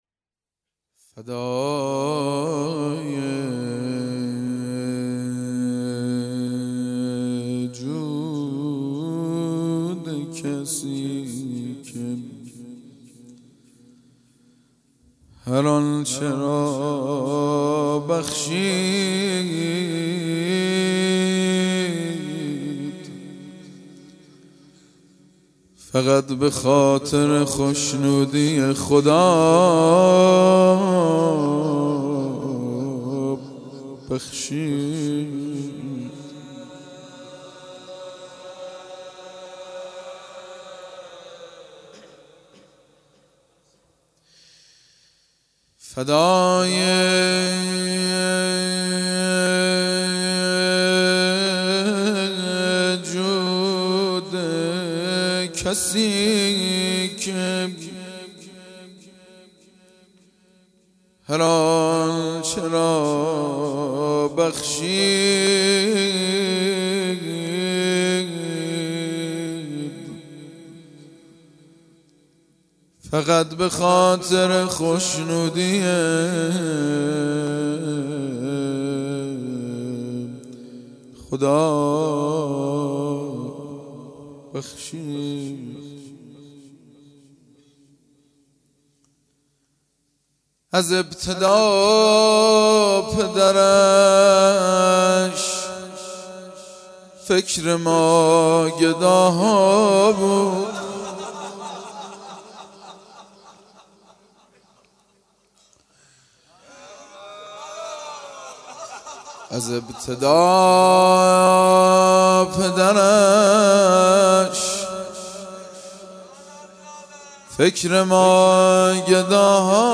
01-Rozeh.mp3